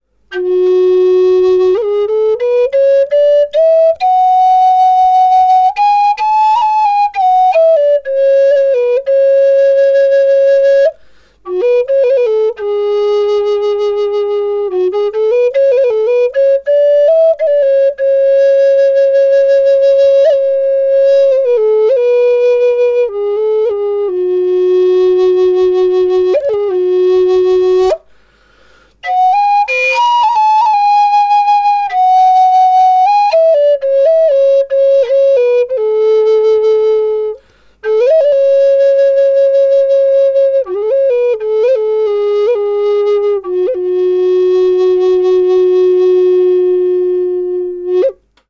Natiiviamerikkalaishuilu F#4 sävellajissa. Matalahko ja lempeä sointitaajuus. Viritetty luonnolliseen molli sävelasteikkoon (tunnetaan myös nimellä Aeolian).
• Vire: Aeolian/Luonnollinen molli (440 hz)
• Puut: Koivu
Ääninäyte ilman efektejä (dry):
Fis4_aeolian_DRY.mp3